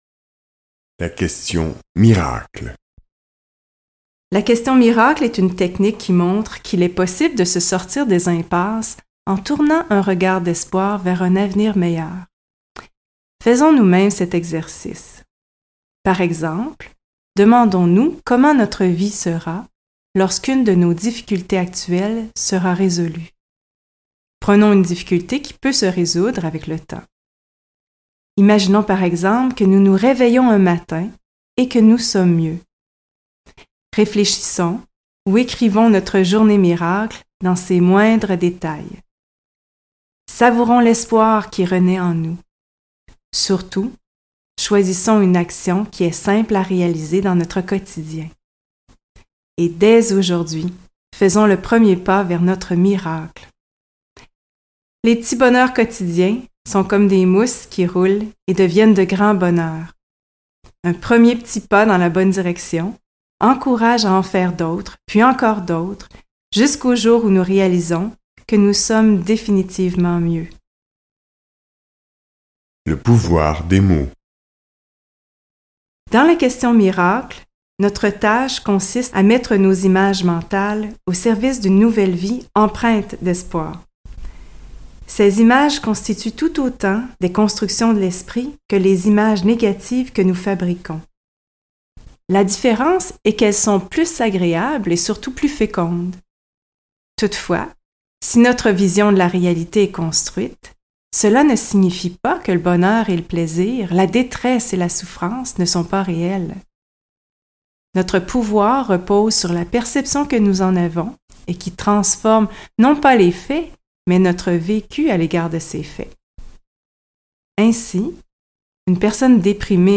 Ce livre audio vous donne des outils concrets pour accroître votre bien-être au quotidien.